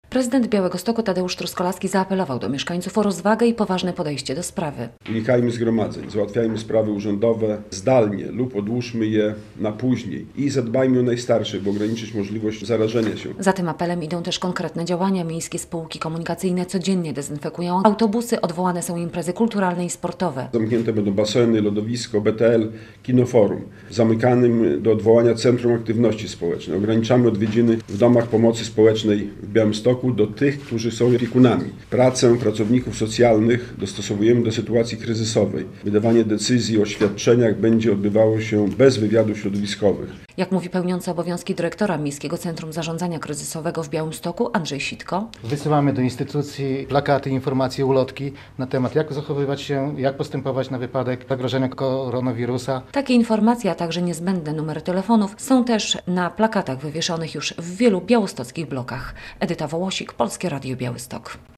Prezydent Białegostoku w zw. z koronawirusem zamyka niektóre placówki i odwołuje imprezy - relacja
- powiedział na konferencji prasowej prezydent Truskolaski.